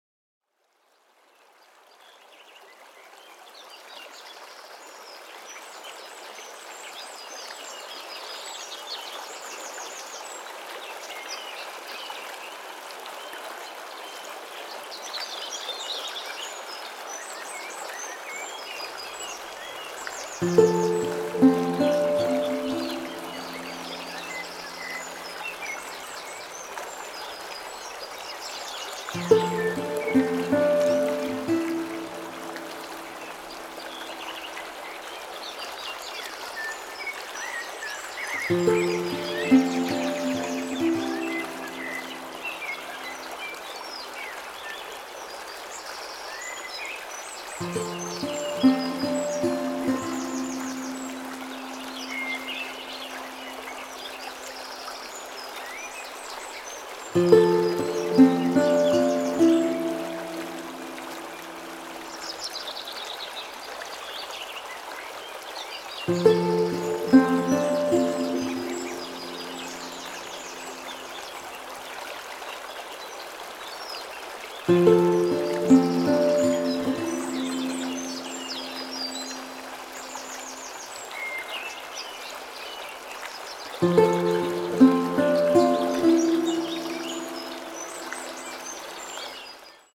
Contemporary
Lute , Relaxing / Meditative